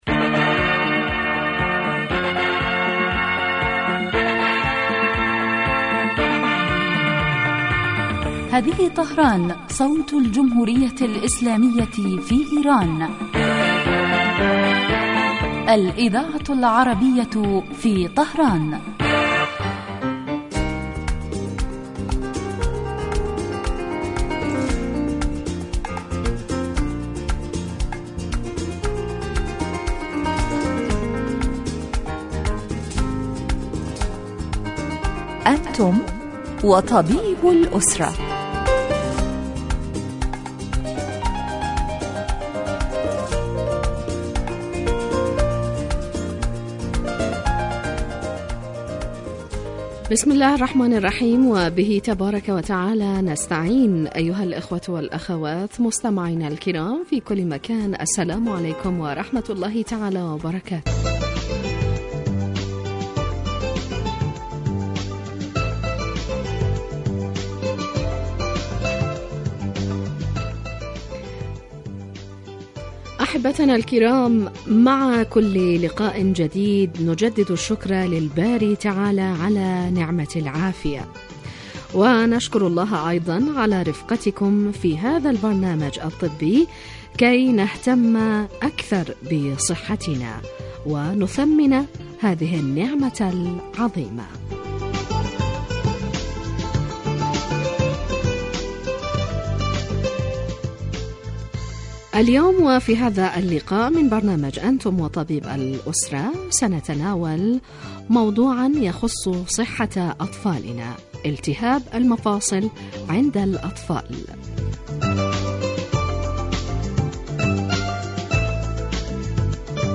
يتناول البرنامج بالدراسة والتحليل ما يتعلق بالأمراض وهو خاص بالأسرة ويقدم مباشرة من قبل الطبيب المختص الذي يرد كذلك علي أسئلة المستمعين واستفساراتهم الطبية